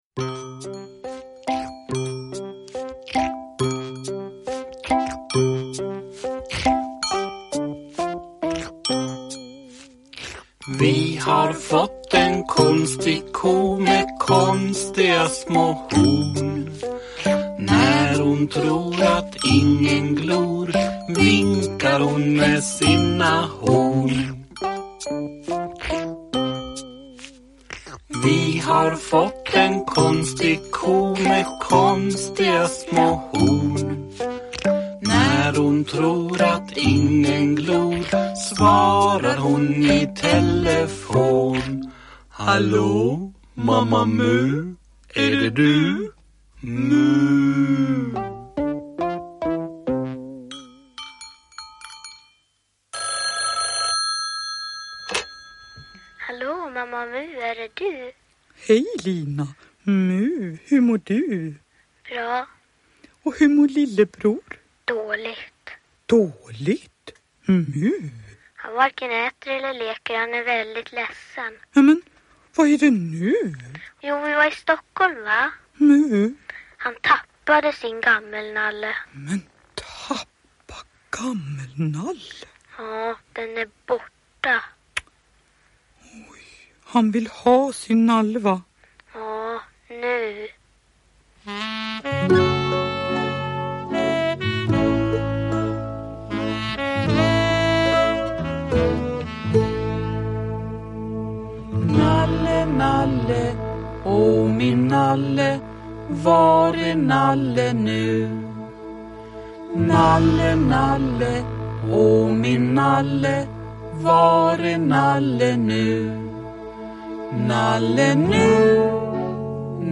Dramatisering med musik.